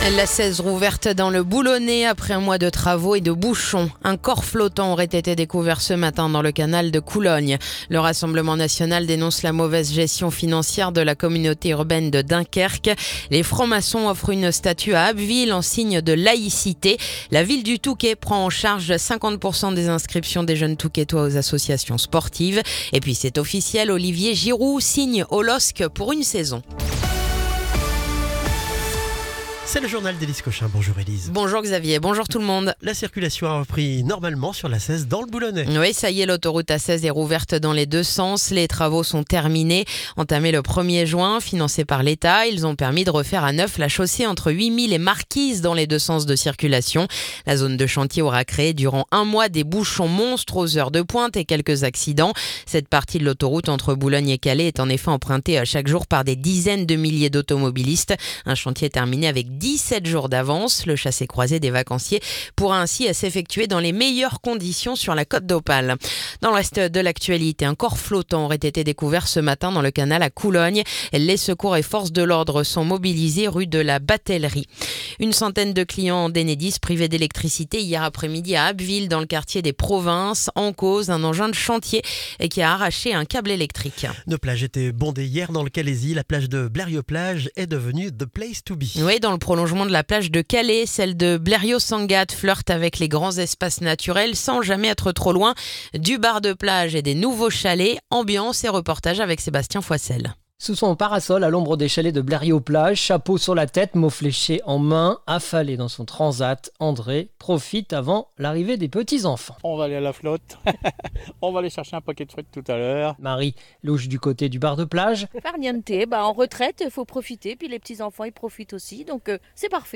Le journal du mercredi 2 juillet